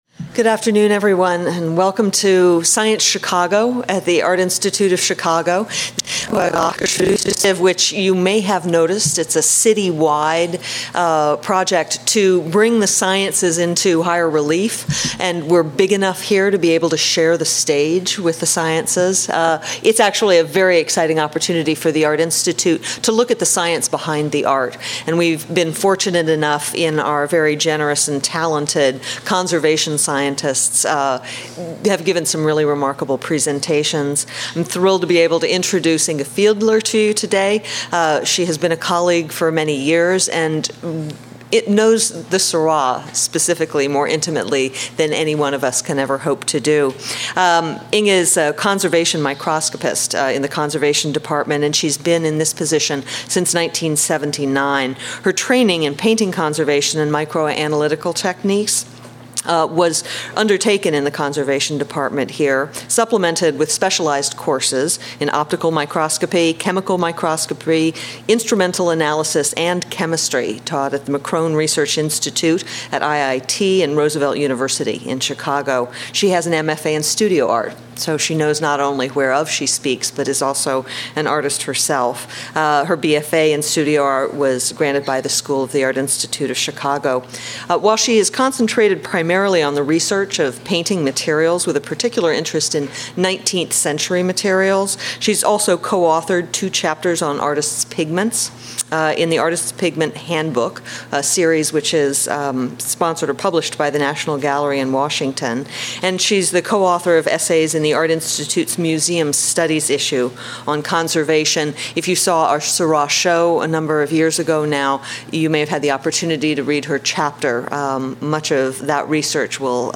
Audio Lecture: Science Chicago—A Technical Study of A Sunday on La Grande Jatte